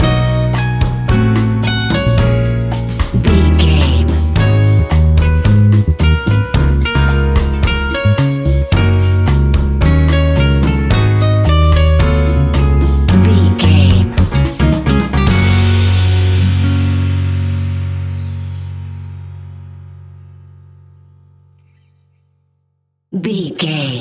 An exotic and colorful piece of Espanic and Latin music.
Ionian/Major
F#
flamenco
romantic
maracas
percussion spanish guitar